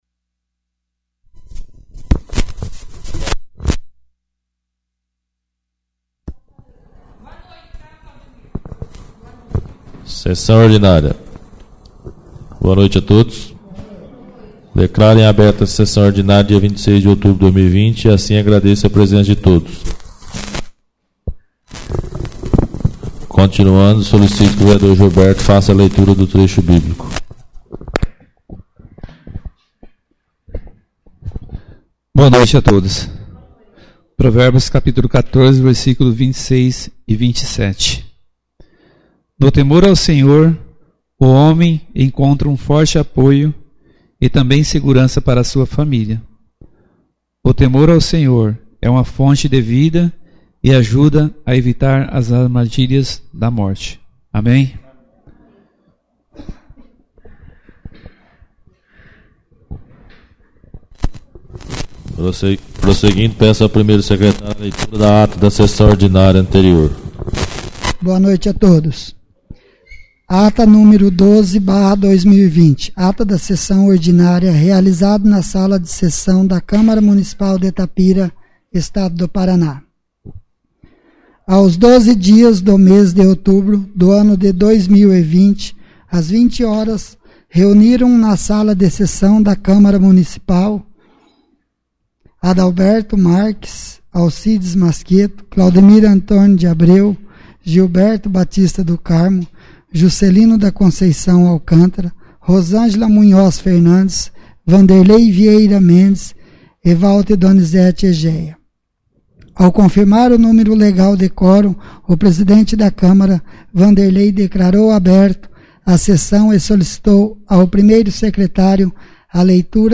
13ª Ordinária da 4ª Sessão Legislativa da 11ª Legislatura